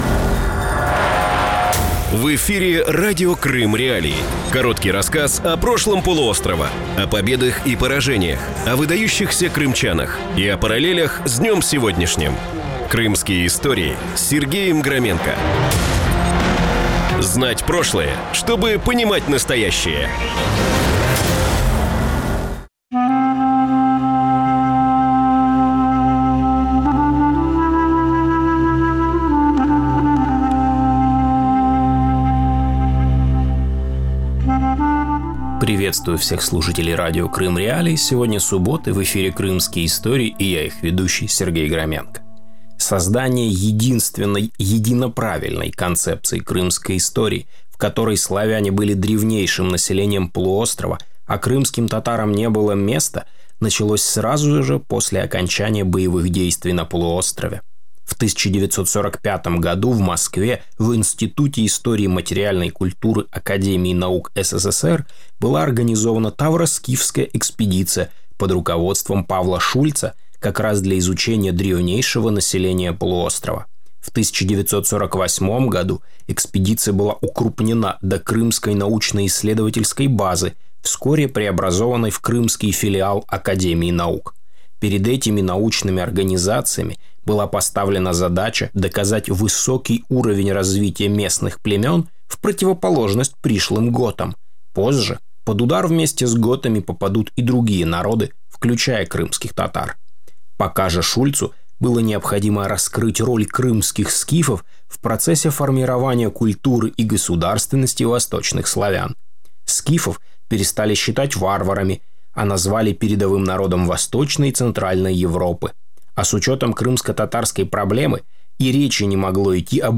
Програма звучить в ефірі Радіо Крим.Реалії. Це новий, особливий формат радіо.